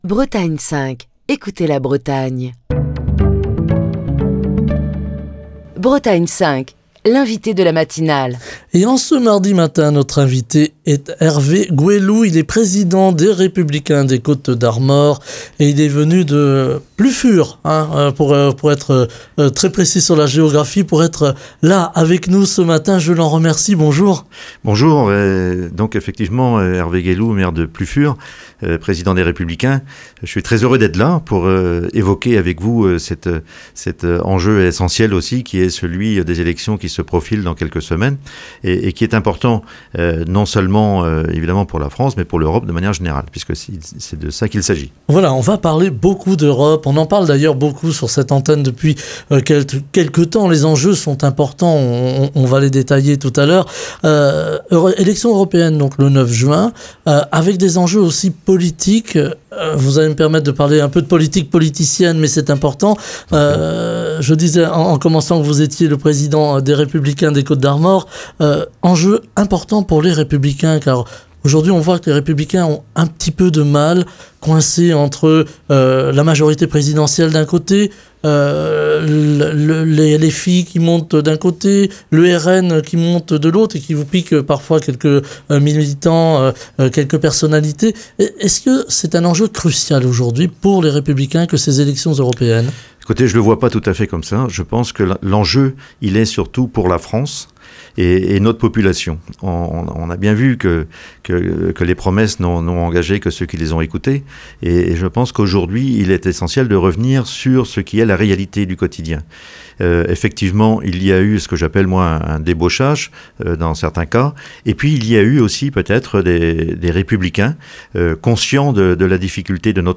Ce matin, Hervé Guélou, président des Républicains des Côtes d'Armor, maire de Plufur (22) est l'invité de Bretagne 5 Matin pour parler d'Europe mais aussi de politique française. A deux mois du scrutin européen prévu le 9 juin, Hervé Guélou explique l'enjeu des européennes pour Les Républicains, alors que le RN qui a vu des personnalités de droite le rejoindre est donné favori dans les sondages.